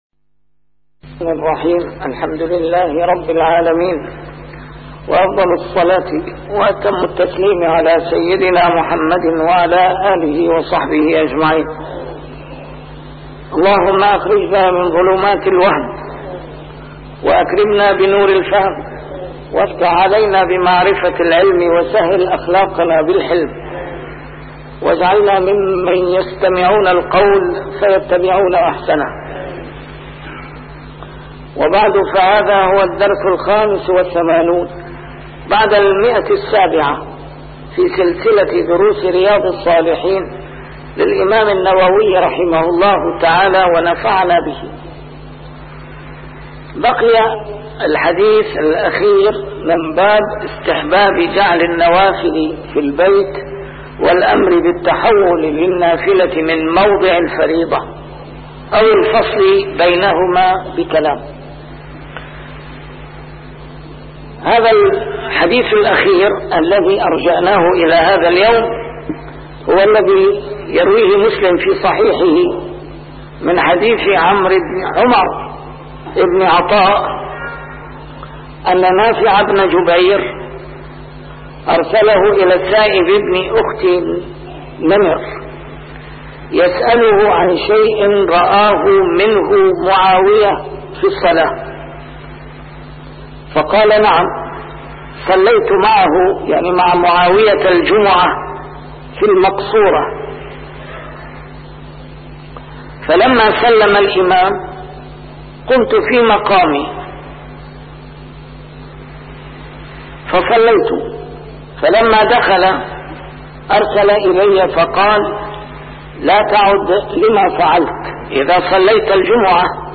A MARTYR SCHOLAR: IMAM MUHAMMAD SAEED RAMADAN AL-BOUTI - الدروس العلمية - شرح كتاب رياض الصالحين - 785- شرح رياض الصالحين: جعل النوافل في البيت - صلاة الوتر